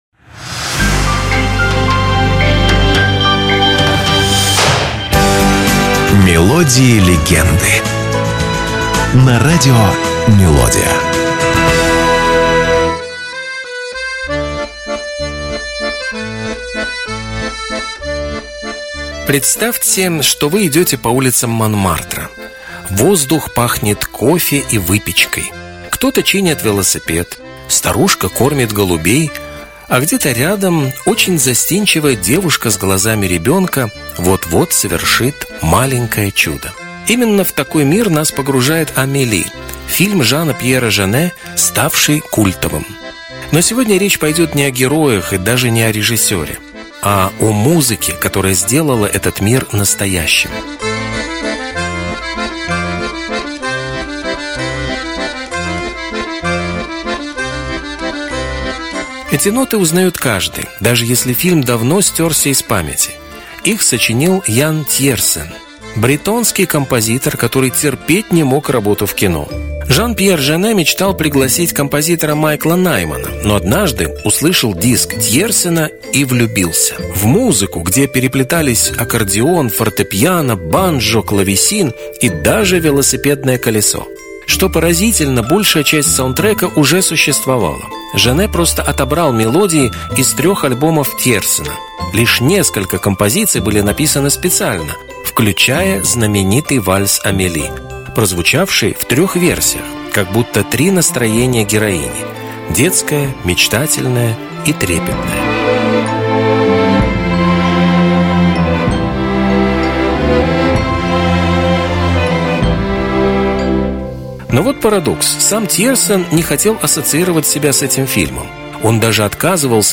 Это короткие музыкальные рассказы с душевным настроением, атмосферой ностальгии и лёгкой интригой. Вы услышите песни, которые звучали десятилетиями и, возможно, впервые узнаете, что стоит за этими знакомыми нотами.